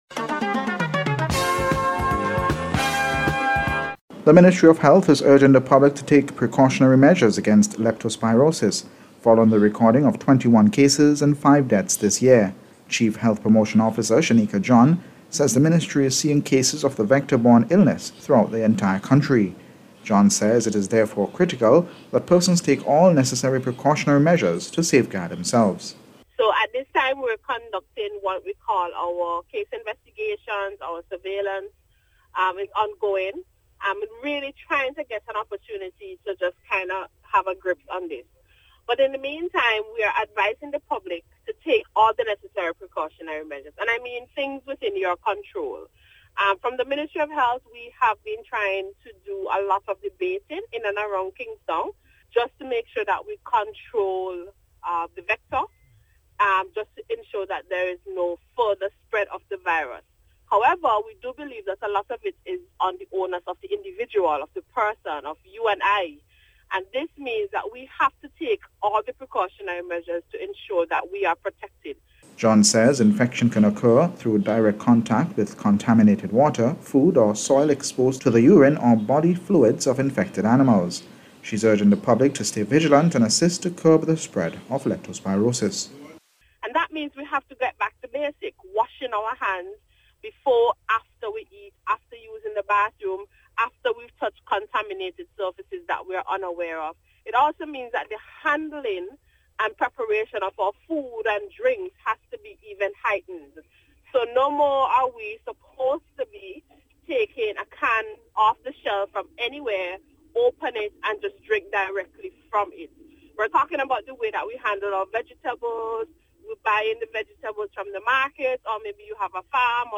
NBC’s Special Report- Friday 17th October,2025